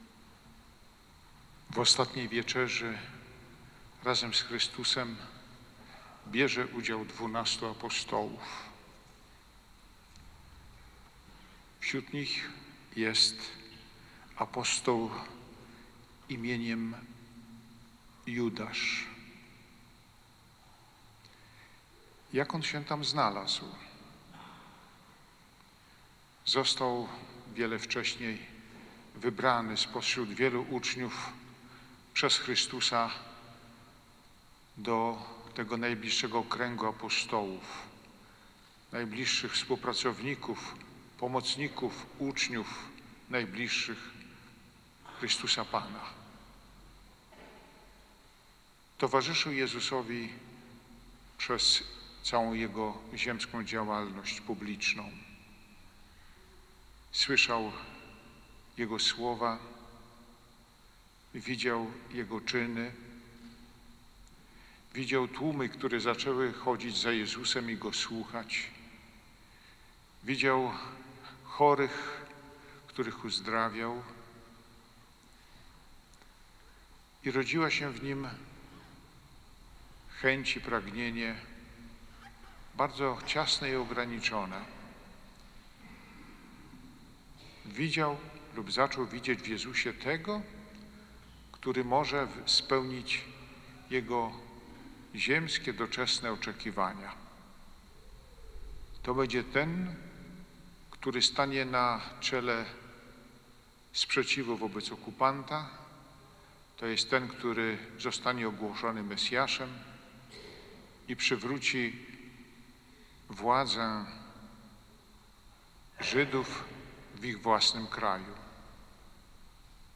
Msza Wieczerzy Pańskiej 2026 – homilia bpa Janusza Ostrowskiego – Archidiecezja Warmińska